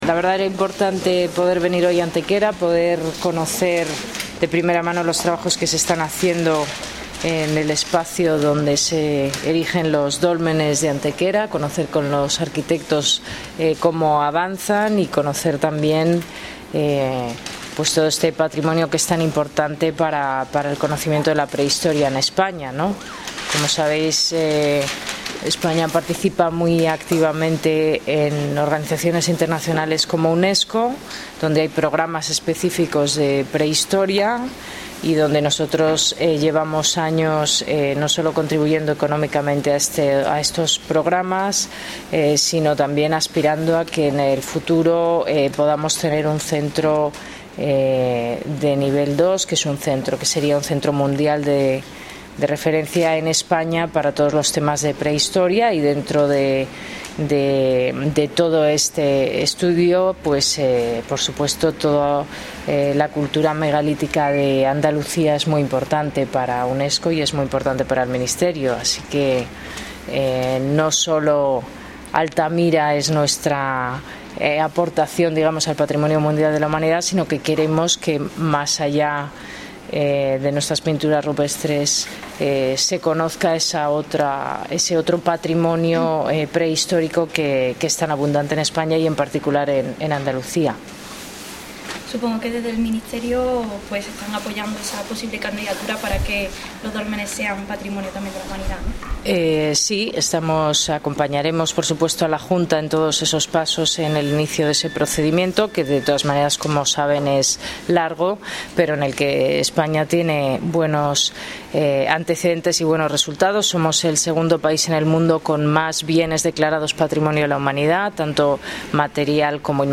Cortes de voz
Audio: ministra de Cultura   2555.13 kb  Formato:  mp3